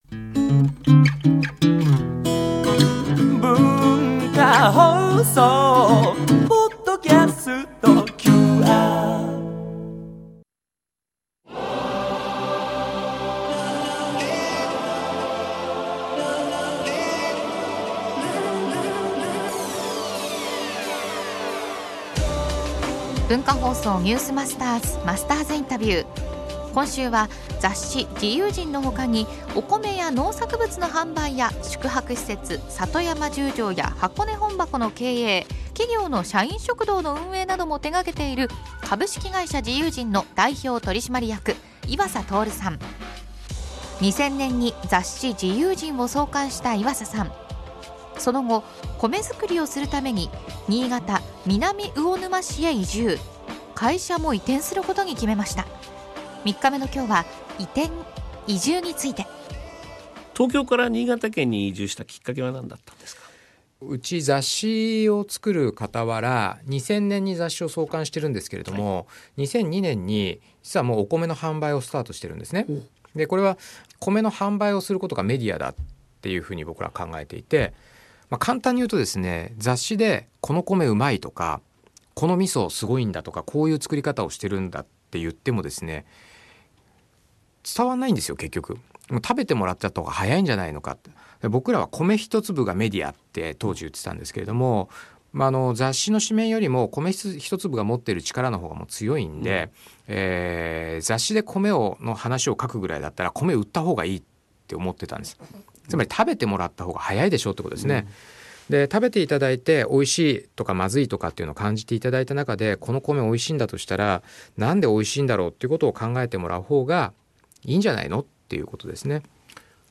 毎週、現代の日本を牽引するビジネスリーダーの方々から次世代につながる様々なエピソードを伺っているマスターズインタビュー。
（月）～（金）AM7：00～9：00　文化放送にて生放送！